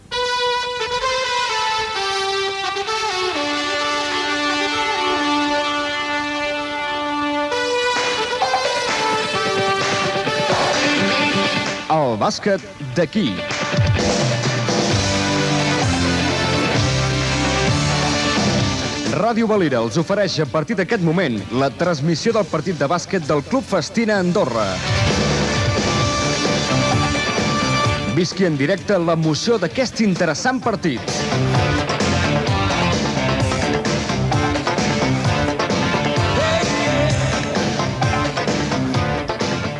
8122f809b0a566985ea644ddc57f8cef1ffec85b.mp3 Títol Ràdio Valira Emissora Ràdio Valira Titularitat Privada local Nom programa El bàsquet d'aquí Descripció Careta de la transmissió del Club Festina Andorra.